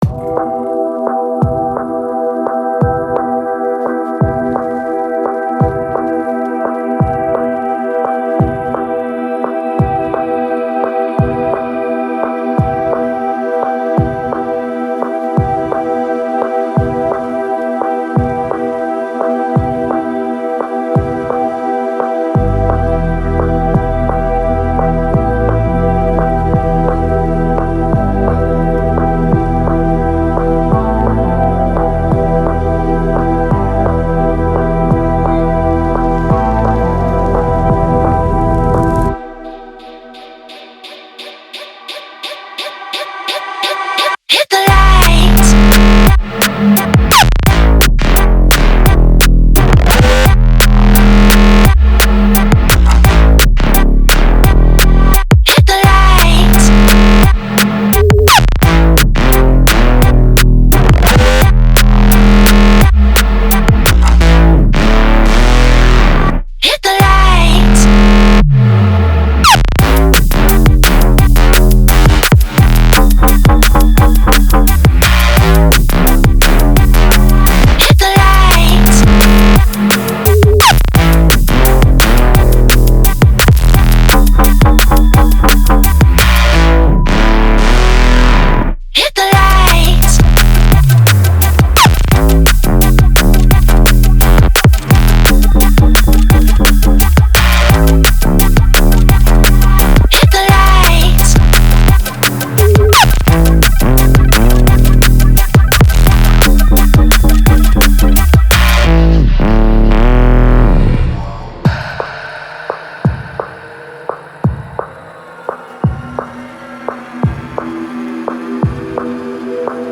• Жанр: Dram&Bass